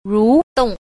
2. 蠕動 – rúdòng – nhu động (lay động)